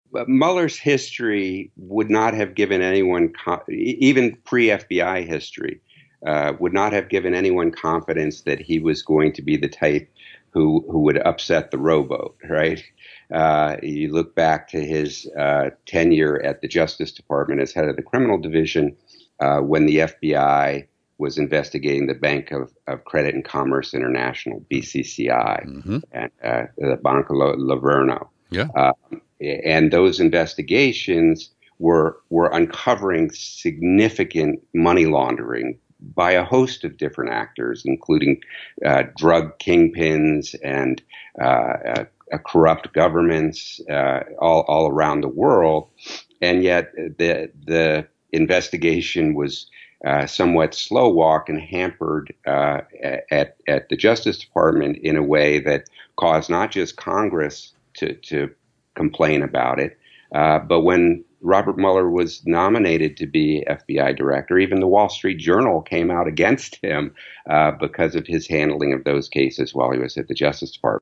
In this hour-long conversation, we start with recent news that the FBI’s Joint Terrorism Task Force and San Francisco Police Dept. have lied about their collaboration since San Francisco dropped out of the JTTF a few years ago.